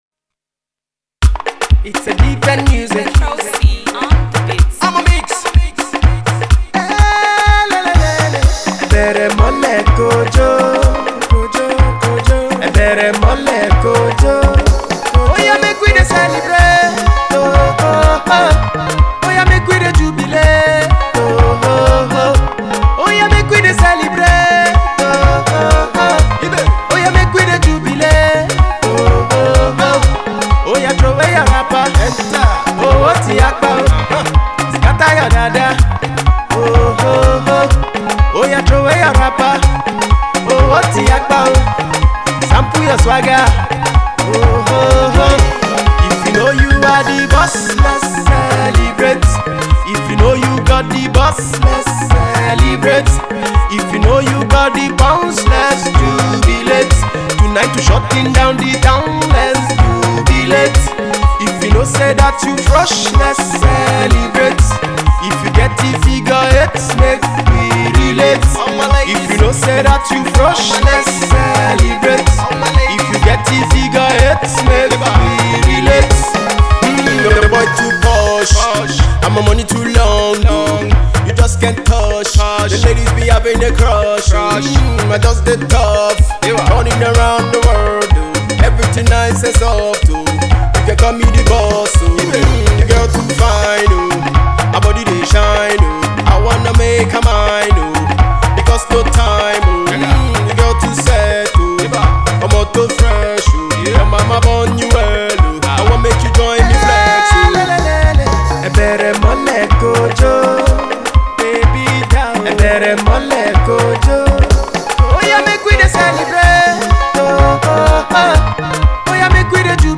Pop
Afro hip hop artist